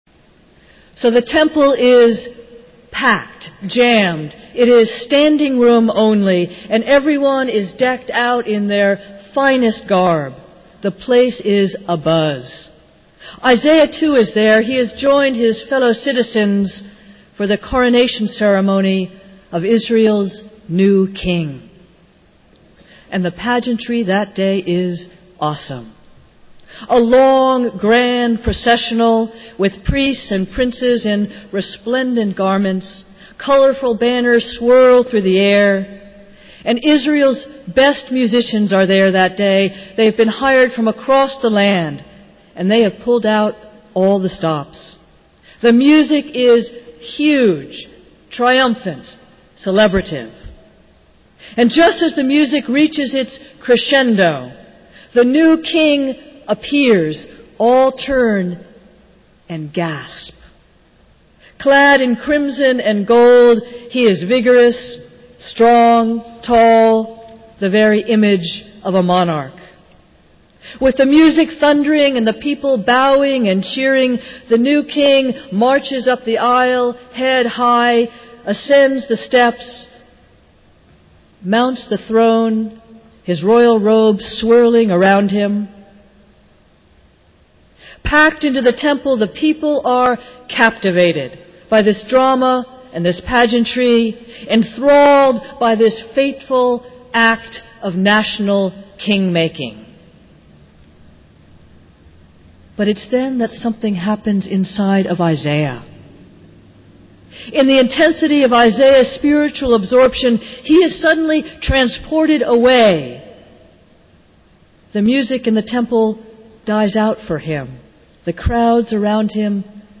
Festival Worship - Sixteenth Sunday after Pentecost